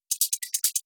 Hihats_Loop C.wav